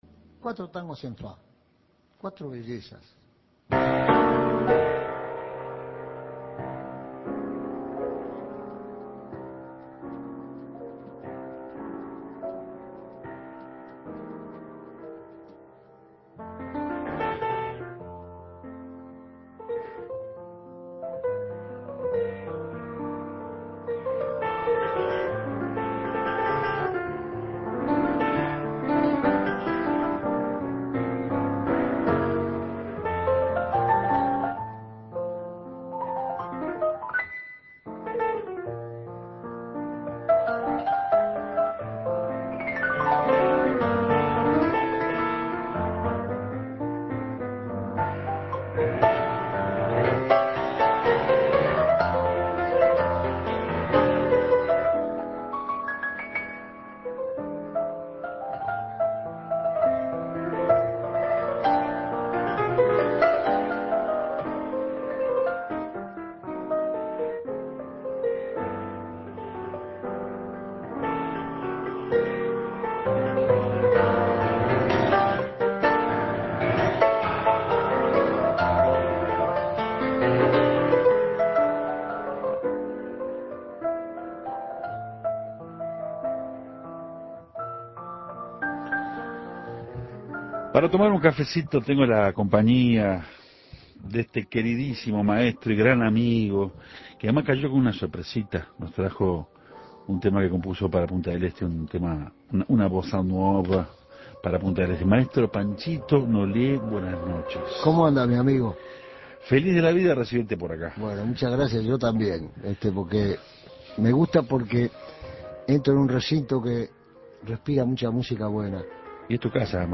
Este cafeto se sirvió entre música, algo de poesía y anécdotas.